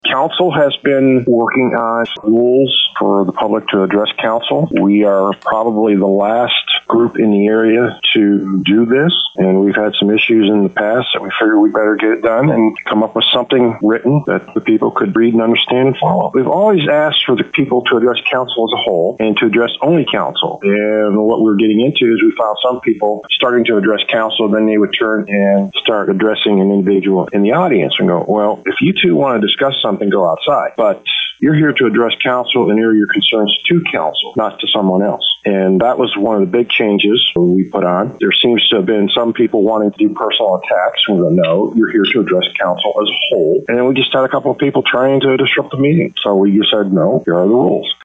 At a council meeting this week, The St Marys City Council discussed rules for public comments at Council Meetings. St Marys City Council President Jim Harris explains: